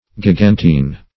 Gigantine \Gi*gan"tine\